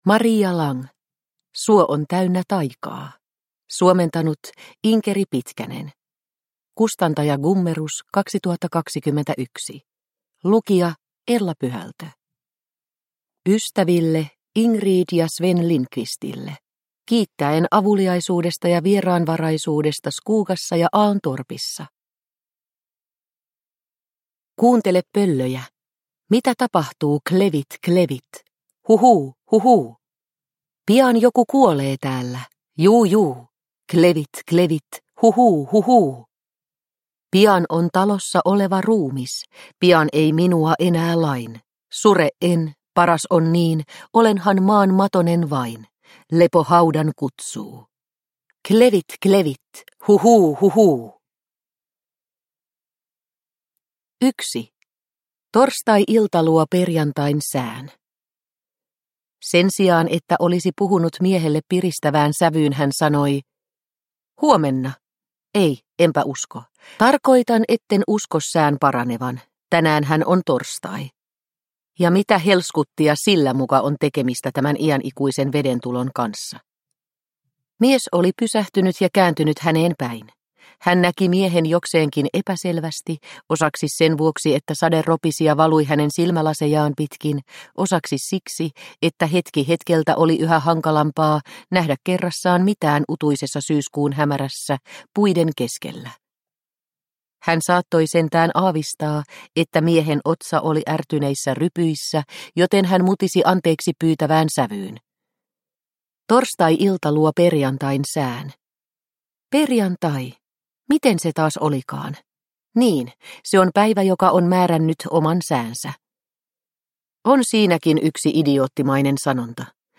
Suo on täynnä taikaa – Ljudbok – Laddas ner